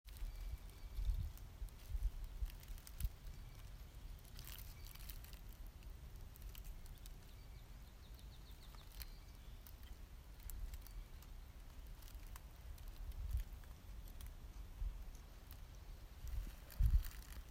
Sound 6 = Leaves in the wind